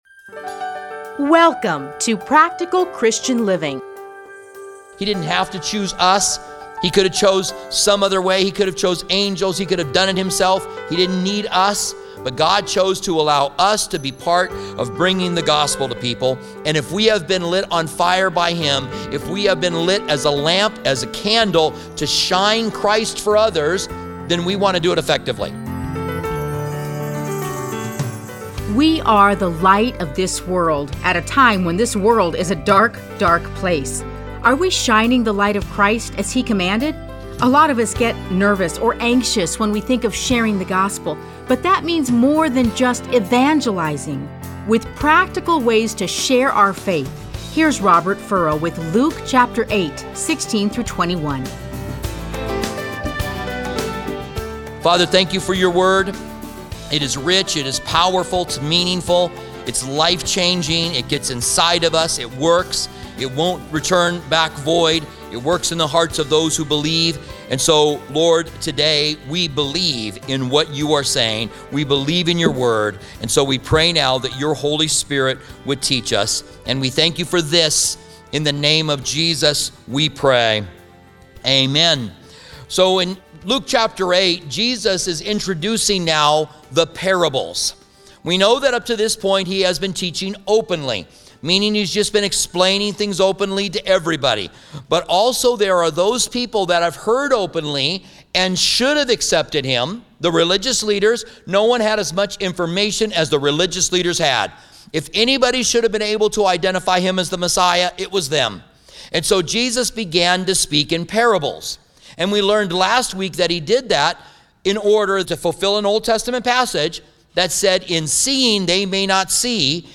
Listen to a teaching from Luke 8:16-21.